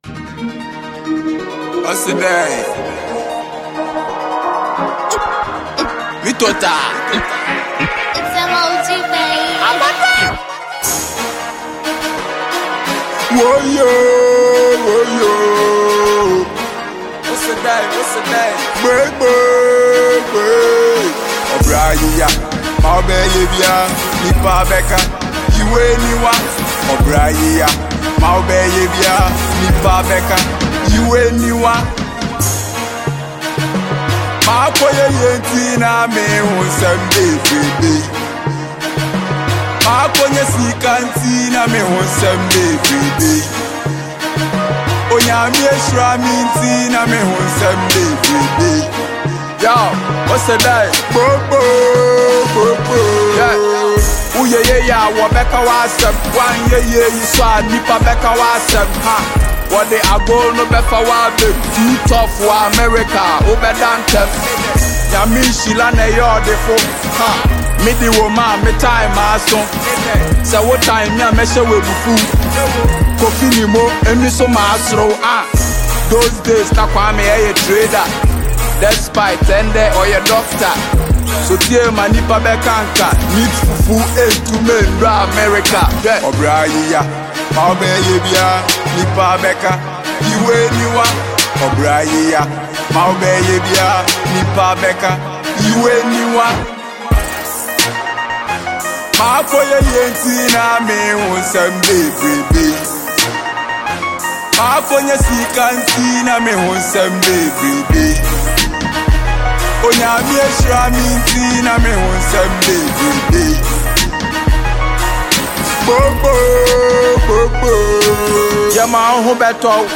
Ghana Music
Ghanaian hardcore rapper